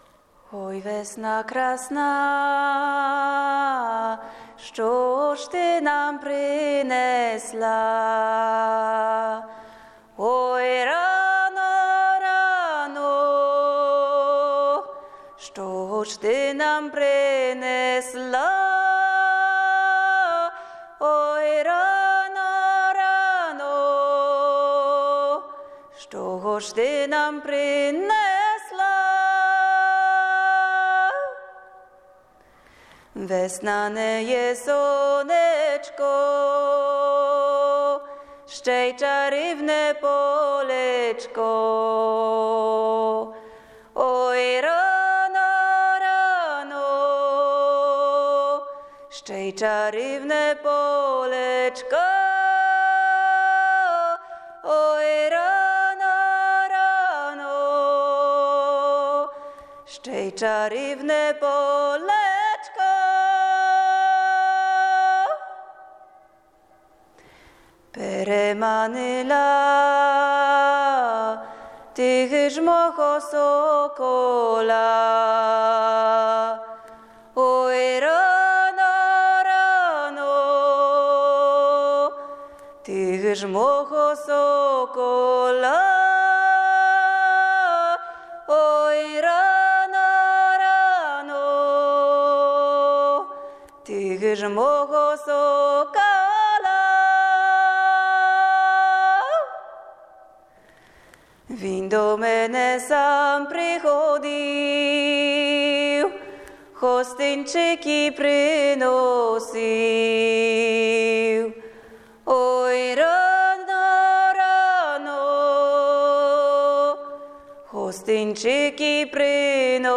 Ukraińskie pieśni pojawiające się w pracy, zostały wykonane w budynku opuszczonej cerkwi greko-katolickiej w Starych Oleszycach (2022).
W nagraniu słychać jej próby przypomnienia sobie tekstu, zapominanie i przypominanie, które zakończone jest donośnym śpiewem, ożywiającym zapomnianą architekturę.